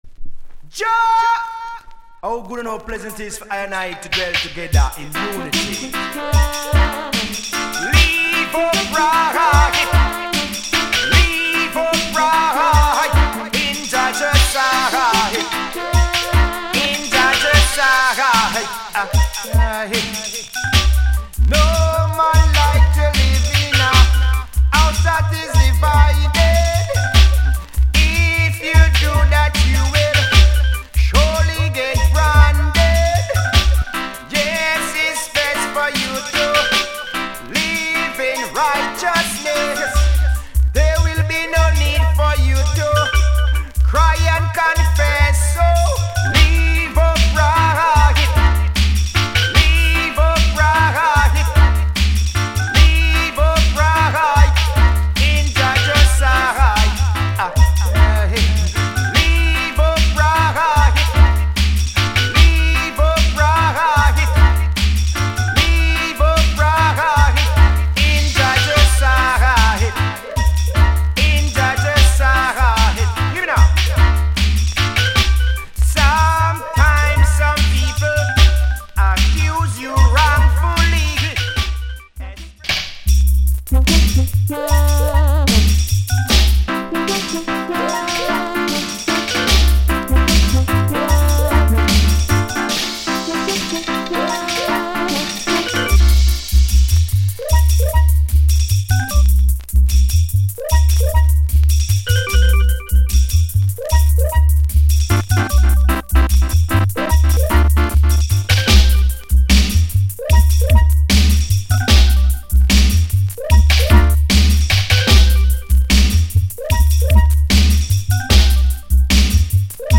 リメイクオケ